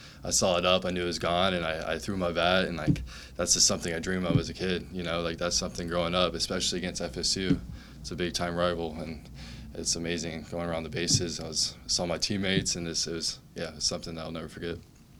In the press conference